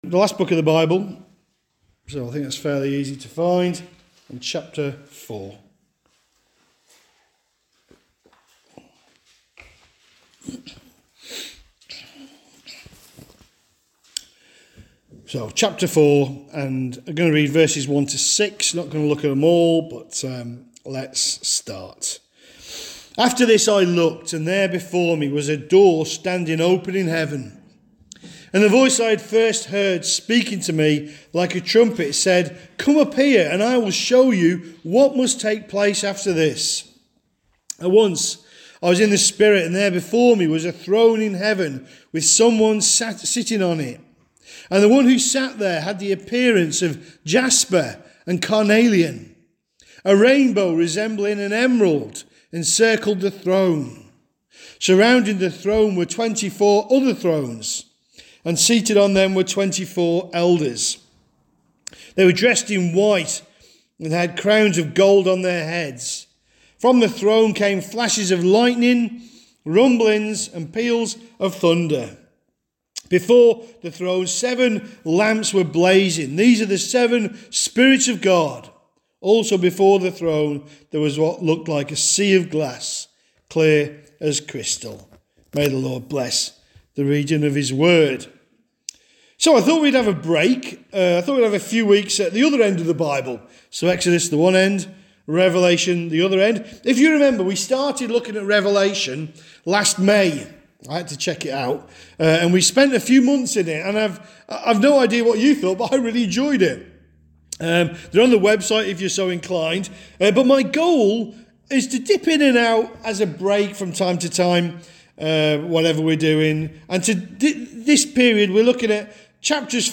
2025 Revelation 4 Part 1 A Heavenly Perspective Preacher